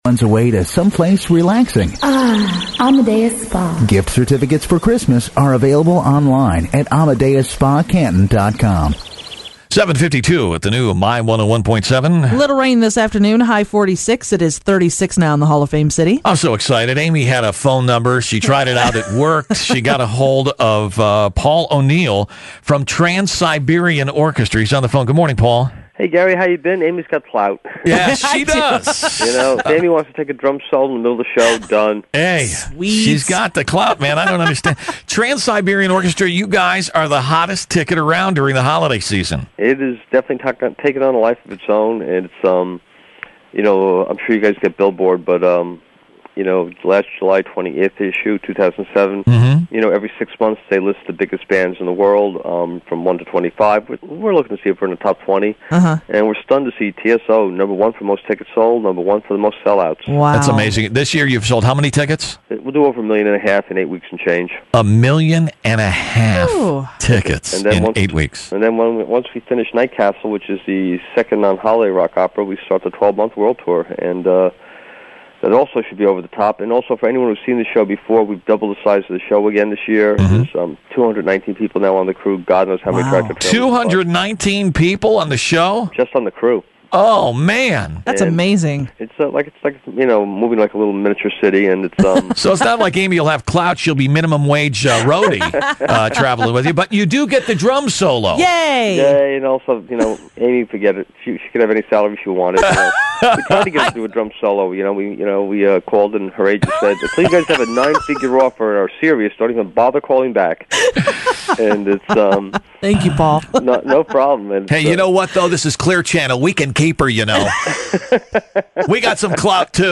I found this interview in my audio archives.